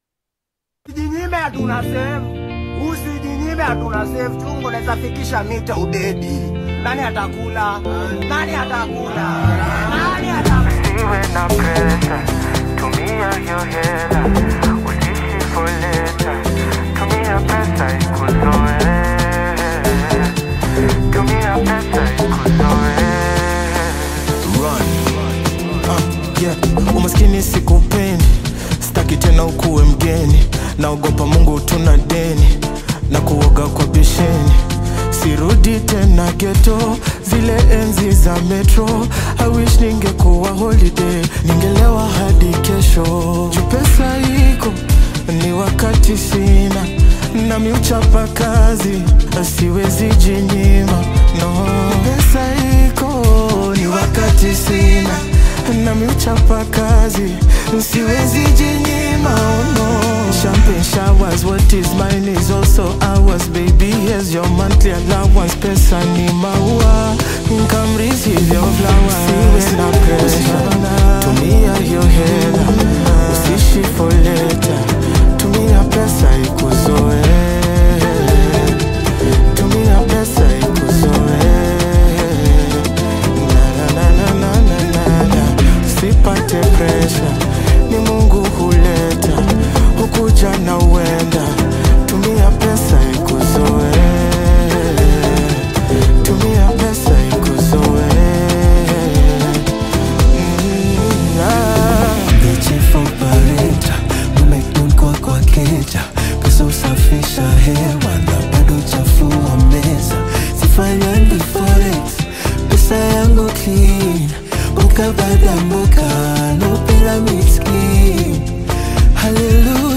energetic and thought-provoking track
catchy Bongo Flava rhythms
With its vibrant production and relatable theme